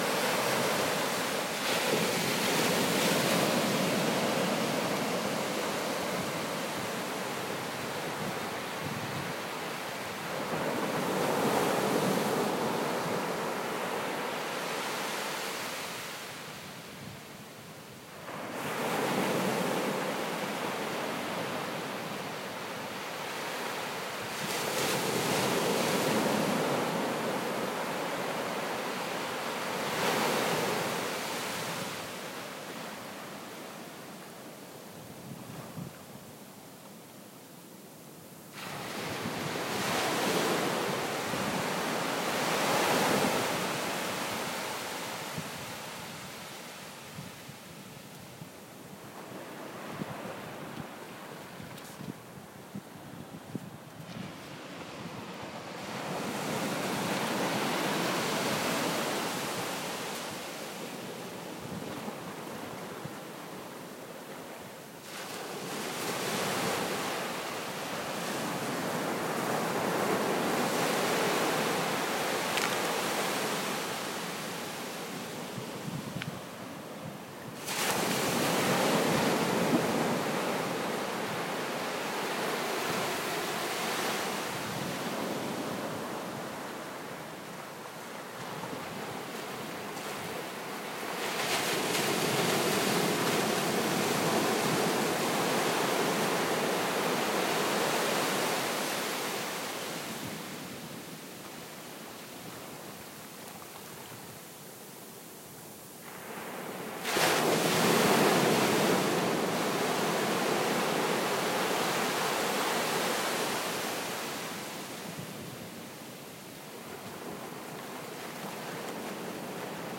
sea-sounds-corn-ar-gazel.m4a